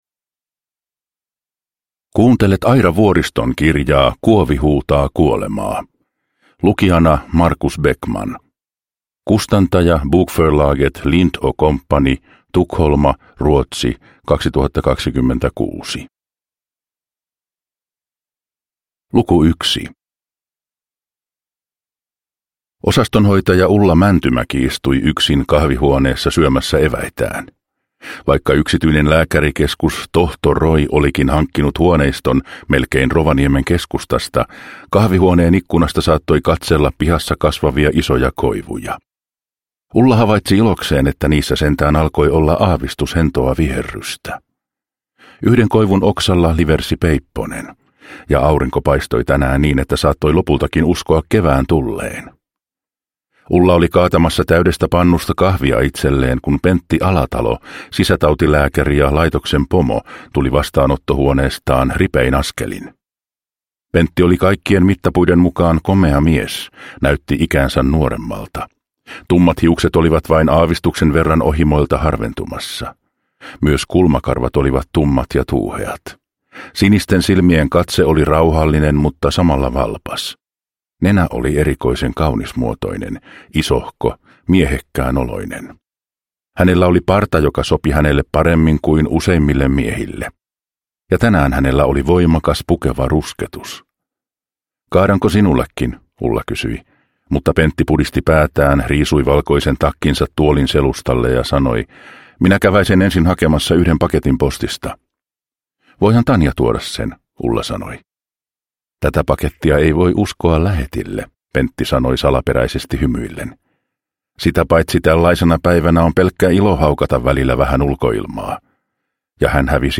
Kuovi huutaa kuolemaa (ljudbok) av Aira Vuoristo